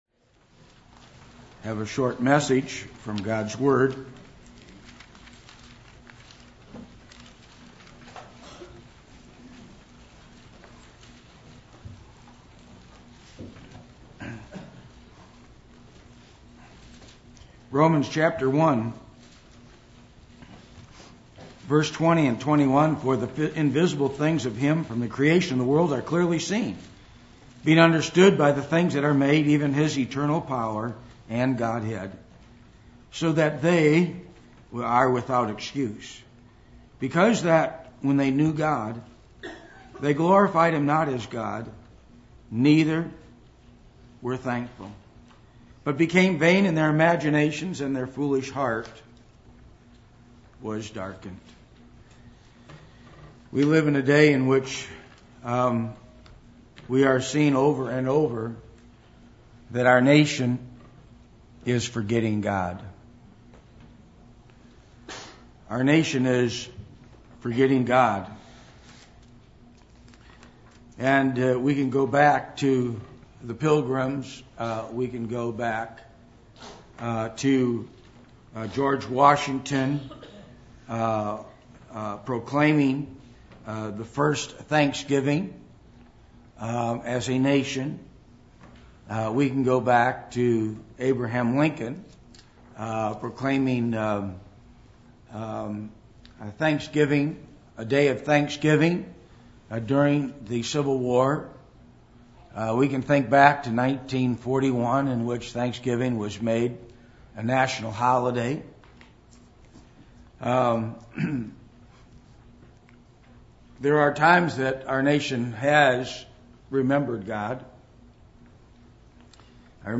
Passage: Romans 1:20-21 Service Type: Thanksgiving